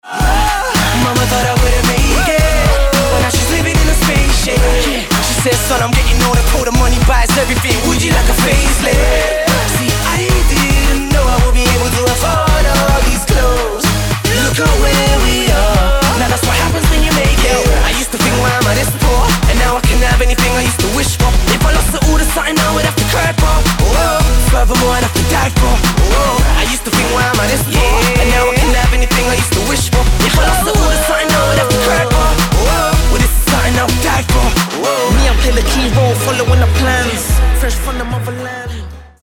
britský rapper